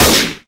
snd_sniper.ogg